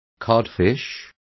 Complete with pronunciation of the translation of codfishes.